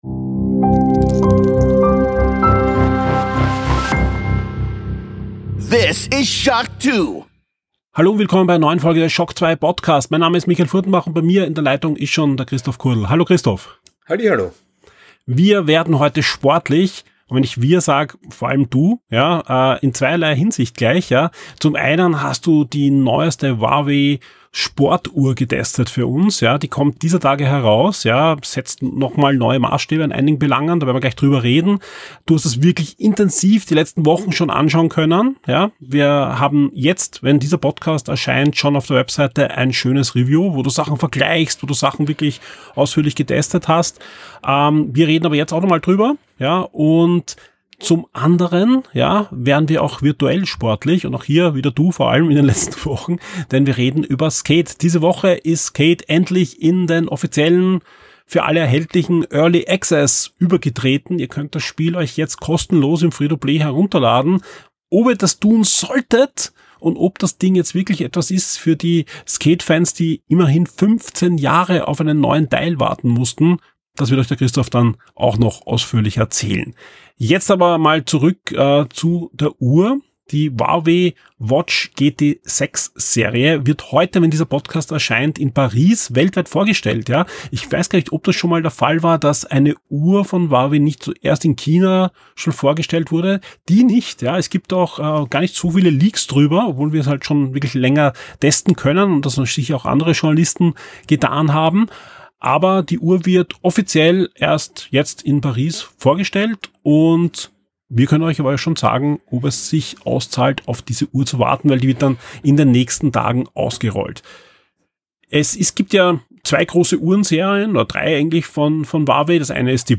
Download - Audio-Review - Deadpool & Wolverine (Spoilerfrei!)